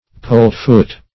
Search Result for " polt-foot" : The Collaborative International Dictionary of English v.0.48: Polt-foot \Polt"-foot`\, Polt-footed \Polt"-foot`ed\, a. Having a distorted foot, or a clubfoot or clubfeet.